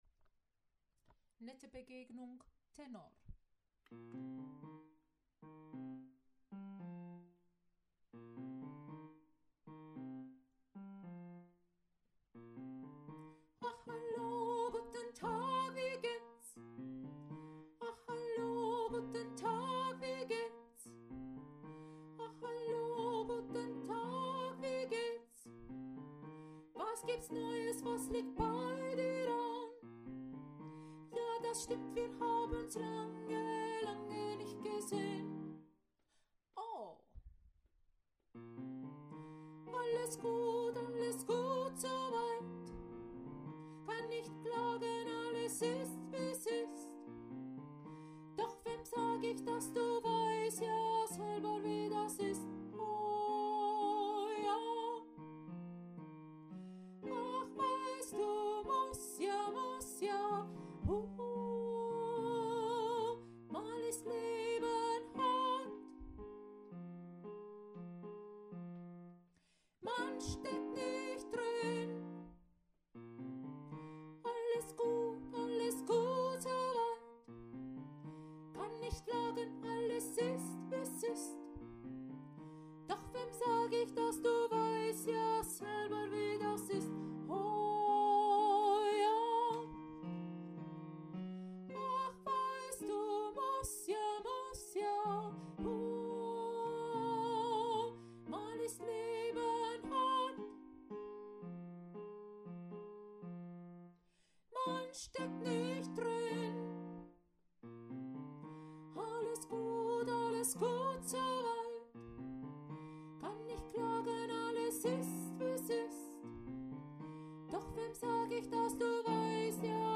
04 - Tenor - ChorArt zwanzigelf - Page 3
04 - Tenor - ChorArt zwanzigelf - Page 3 | Der moderne Chor in Urbach
Nette-Begegnung-Tenor.mp3